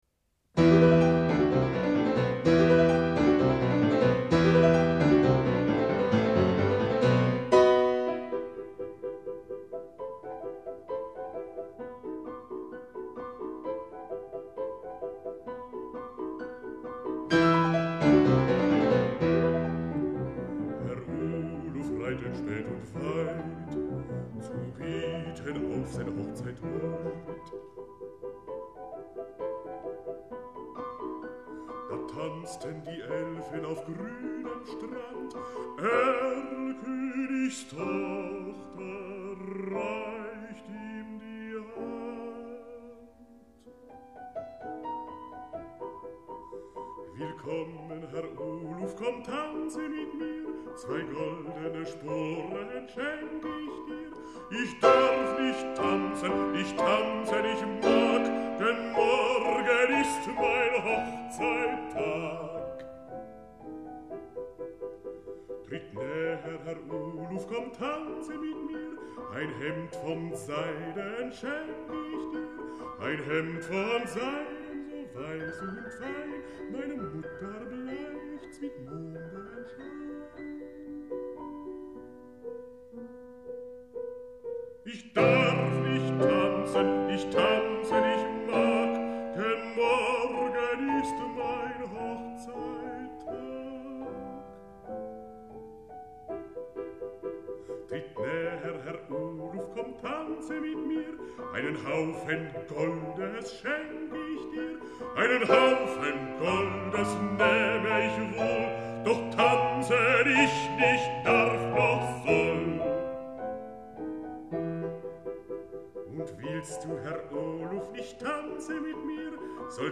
"Herr Oluf" von Carl Löwe, gesungen von Hermann Prey (Phonogram GmbH 1974).